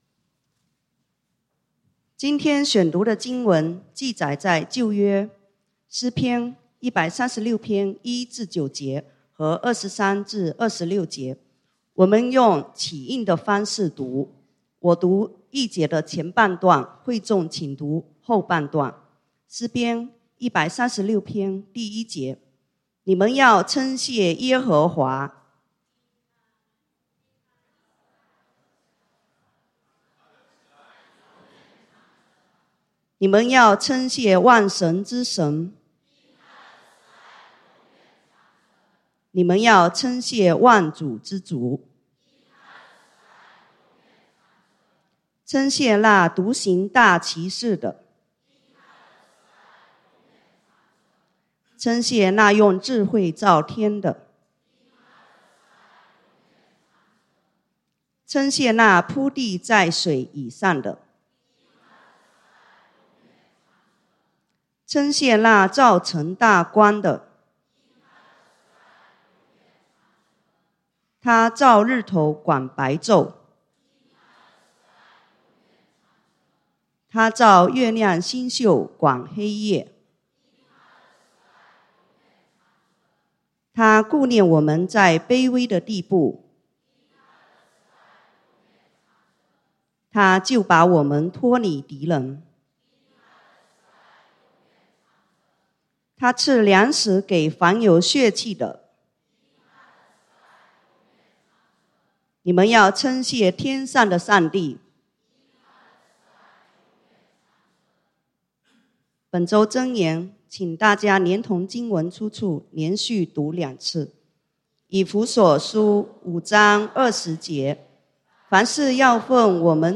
感恩見證分享 (經文：詩篇 136:1-9, 23-26) | External Website | External Website